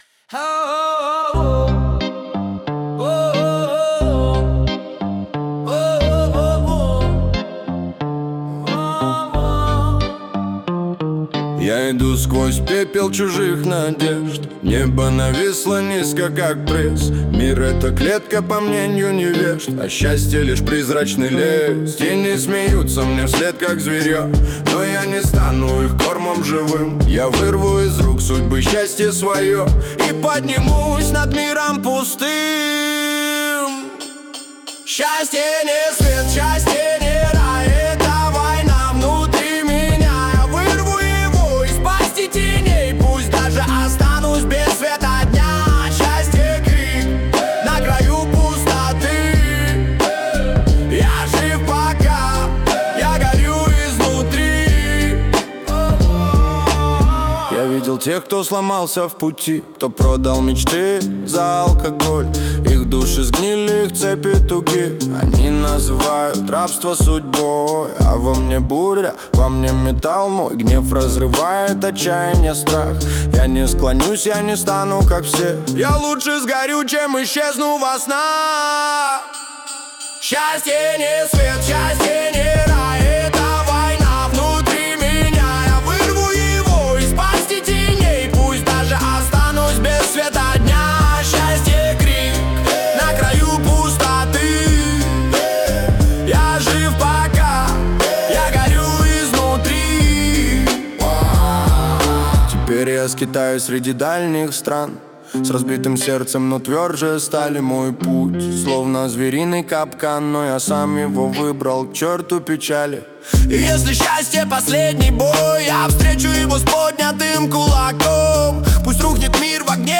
хип-хоп, медленный темп, чёткий бит, синтезатор, атмосферное звучание
Что можно улучшить: синтезатор размытый, типовой, легко теряется в аранжировке.